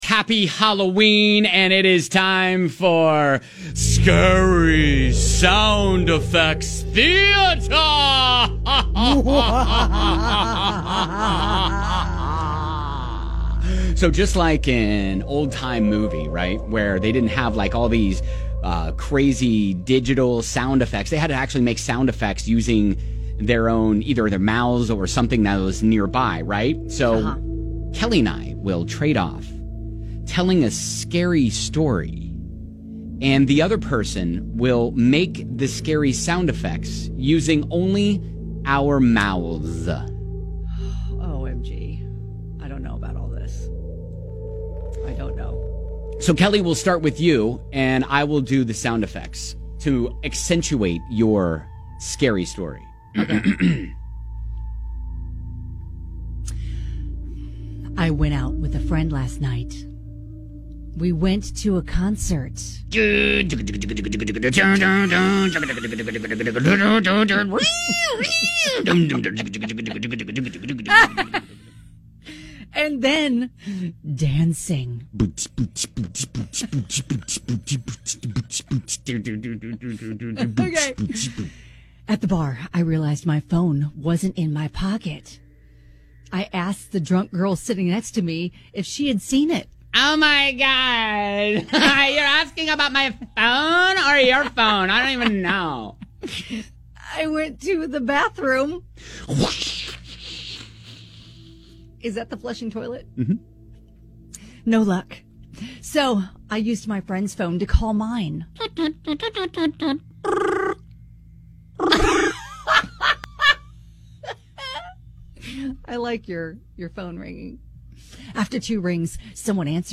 Just like an old-time movie. We’ll trade off telling a scary story and making the scary sound effects ourselves!